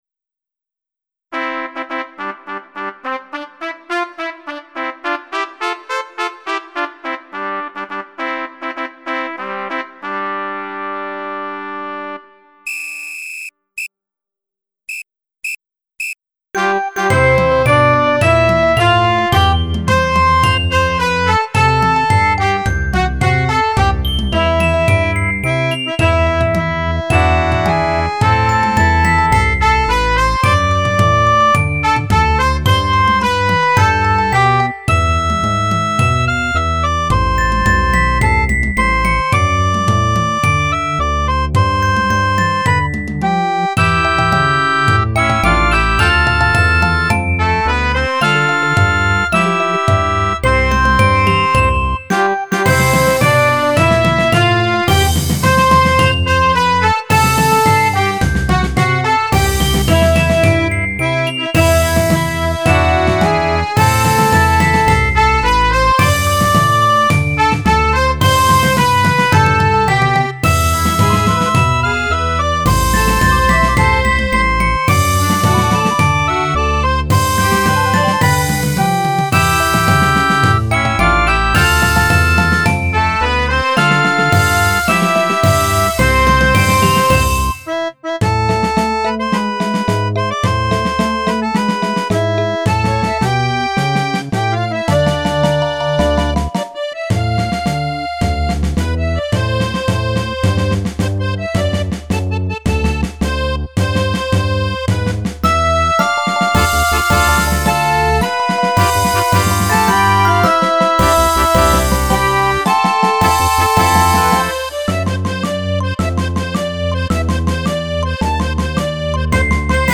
・2024「われら6年生」作詞・作曲・編曲　二部合唱